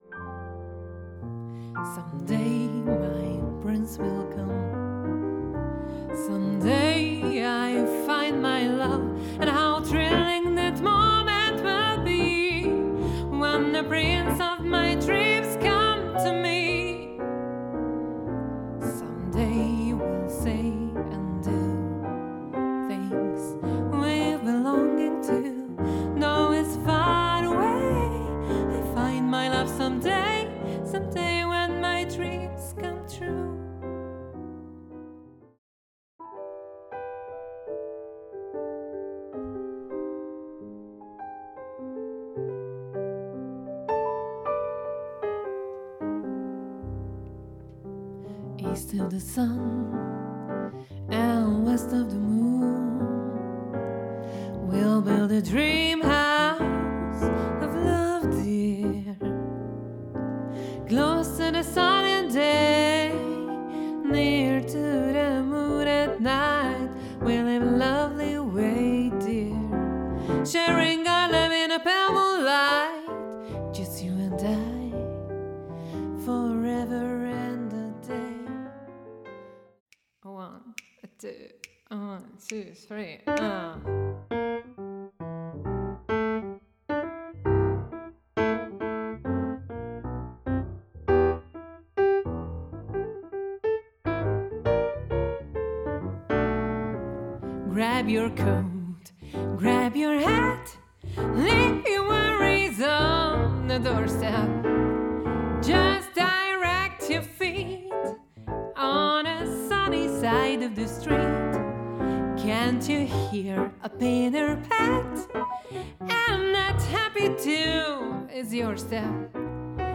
zongora
jazz, blues, soul, pop,szabad improvizáció